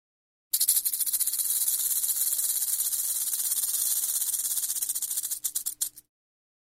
На этой странице собраны разнообразные звуки змей: от устрашающего шипения до угрожающих вибраций хвоста.
Звук погремушки гремучей змеи